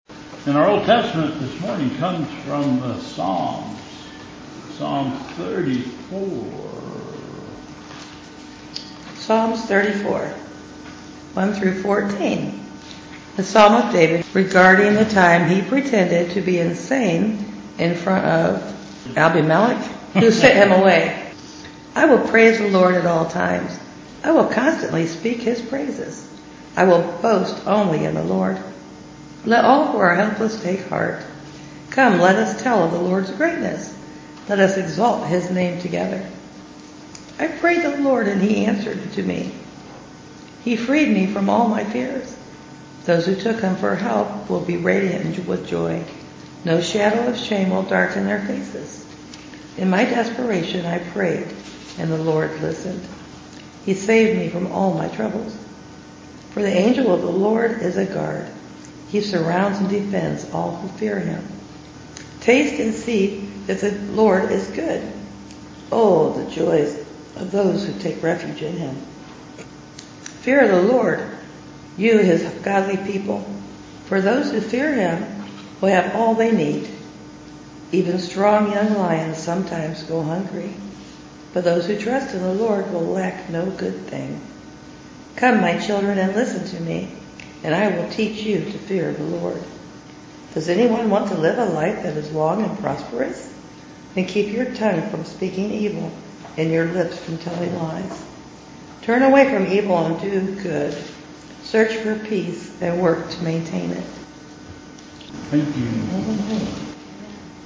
Bethel Church Service
Old Testament Scripture